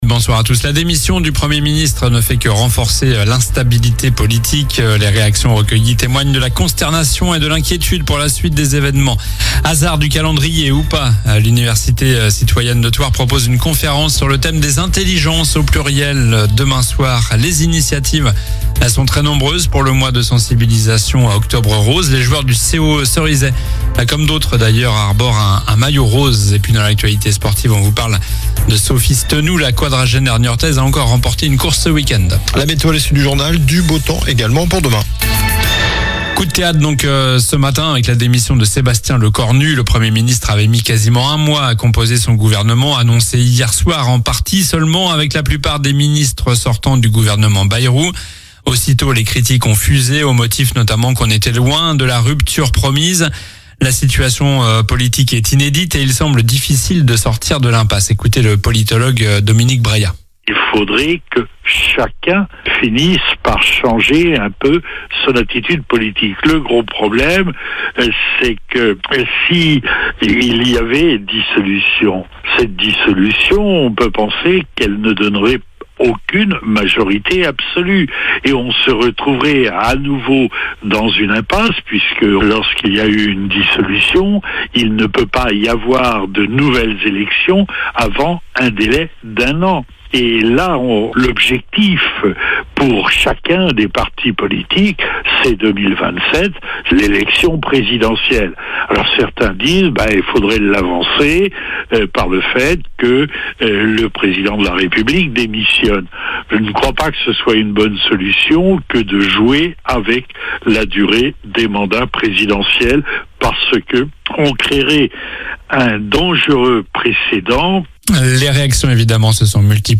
Journal du lundi 6 octobre (soir)